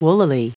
Prononciation du mot woollily en anglais (fichier audio)
woollily.wav